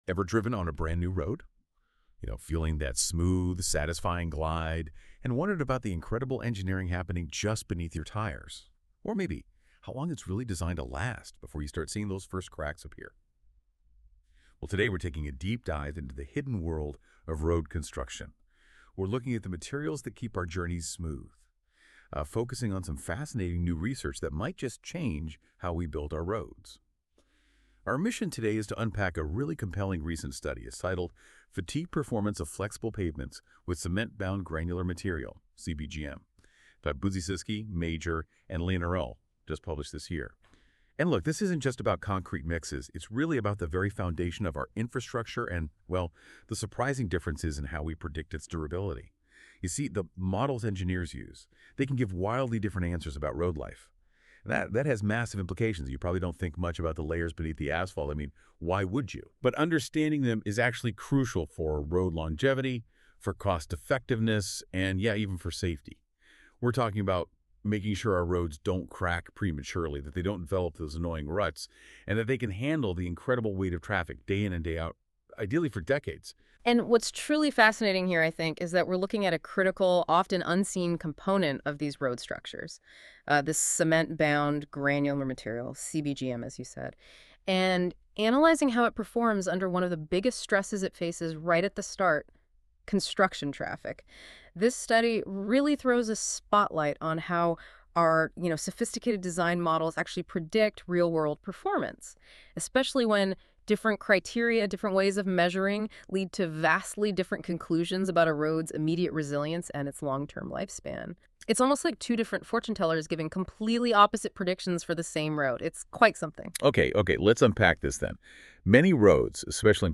Join us for insightful interviews with top researchers, in-depth discussions of groundbreaking papers, and explorations of emerging trends in the field.